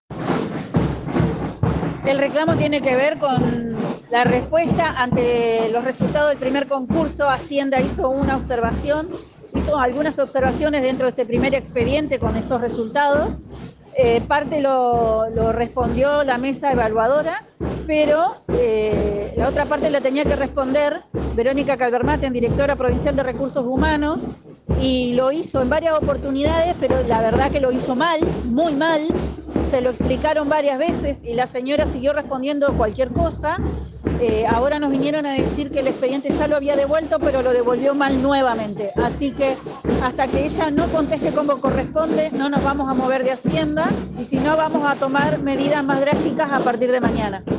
por RÍO NEGRO RADIO
trabajadora de Desarrollo Social